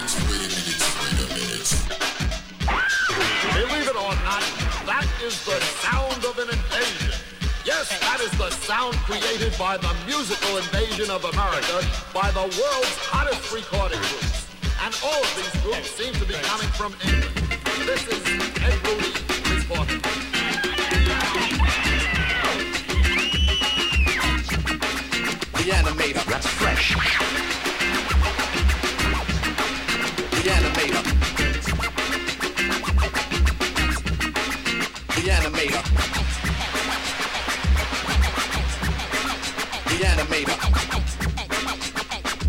現在のテクノのルーツともいえるサウンド。
シンセがキモチワルイA-2はウネウネテクノ。
サイケデリック＆ダビーなテクノB-2。
シャキシャキしたハットがトリッピーなB-3オススメ。